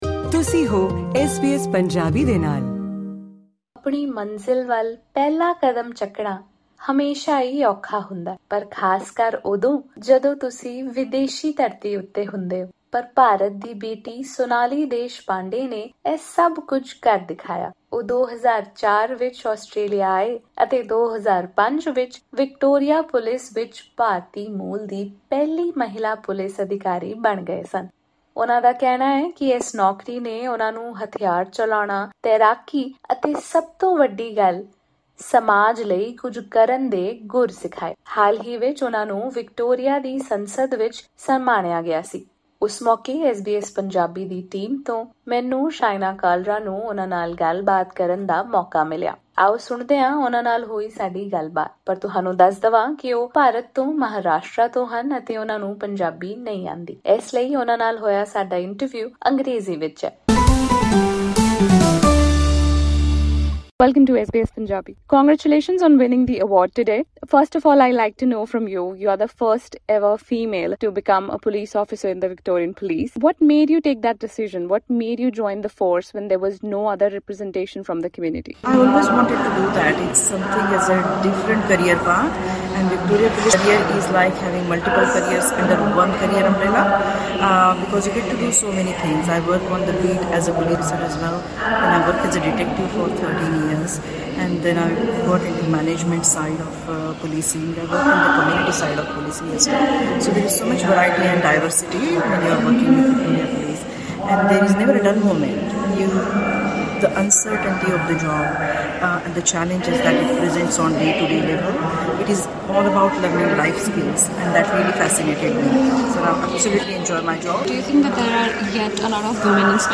ਇਸ ਪੋਡਕਾਸਟ ਰਾਹੀਂ ਸੁਣੋ ਉਨ੍ਹਾਂ ਨਾਲ ਹੁਈ ਐਸ ਬੀ ਐਸ ਪੰਜਾਬੀ ਪੂਰੀ ਗੱਲਬਾਤ: